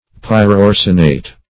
Pyroarsenate \Pyr`o*ar"se*nate\, n.